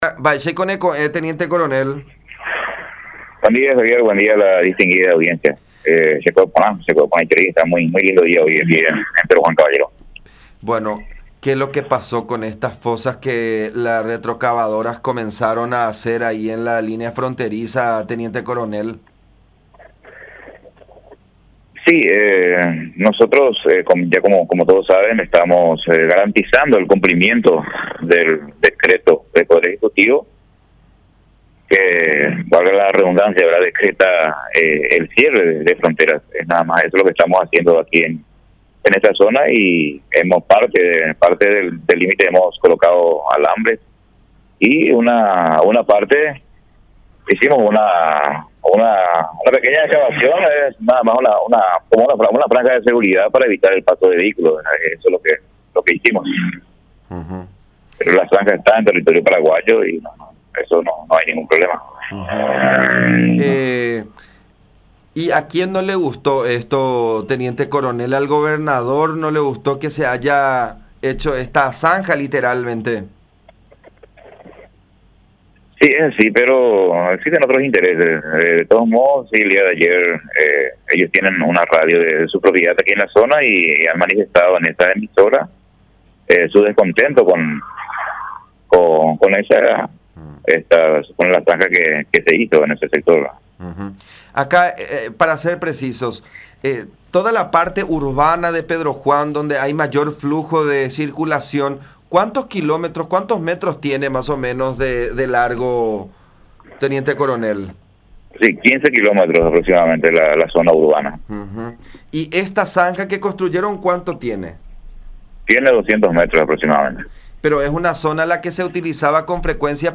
en comunicación con La Unión.